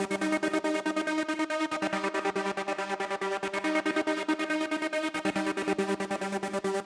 VTS1 Space Of Time Kit Melody & Synth